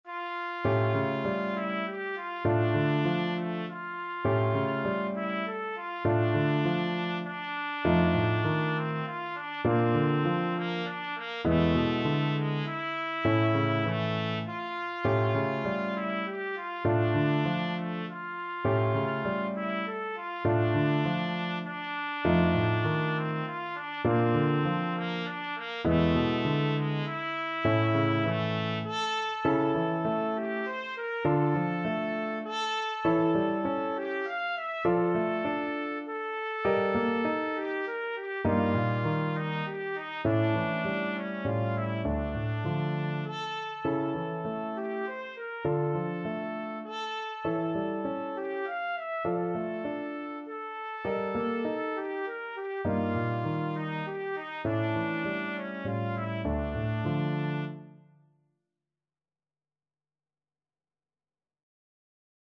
Trumpet
D minor (Sounding Pitch) E minor (Trumpet in Bb) (View more D minor Music for Trumpet )
3/4 (View more 3/4 Music)
Etwas bewegt
Classical (View more Classical Trumpet Music)
fuchs_op47_5_TPT.mp3